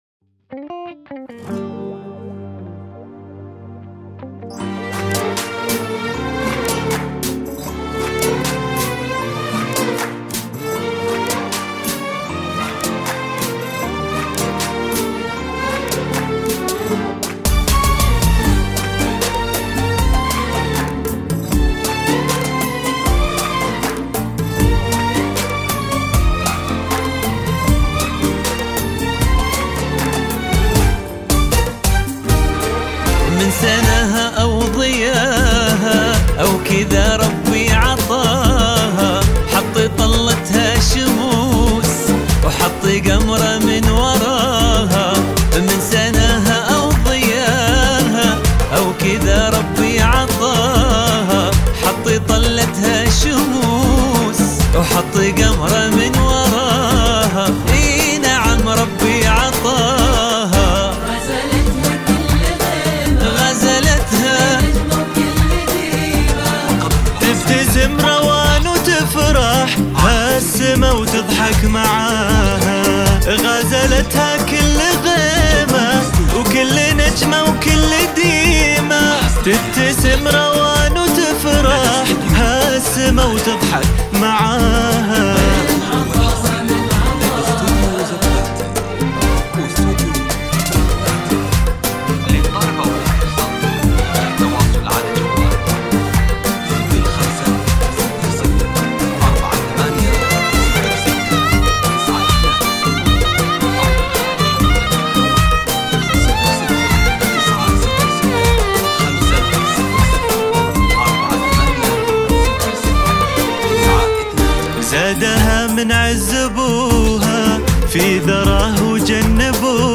زفة كوشة
بدون موسيقى ايقاع